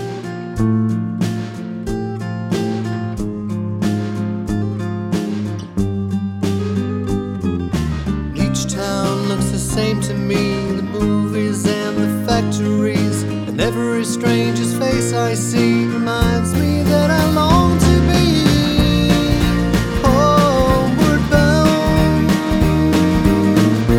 With Harmony Pop (1960s) 2:28 Buy £1.50